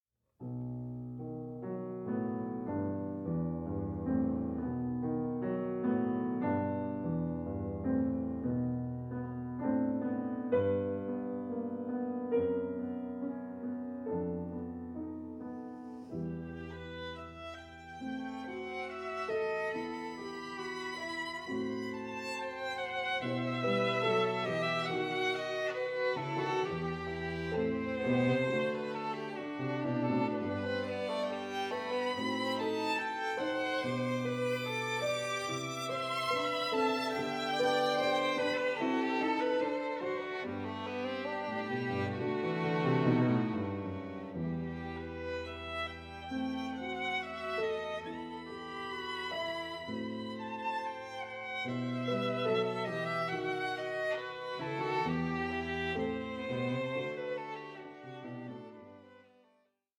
Lento assai 3:13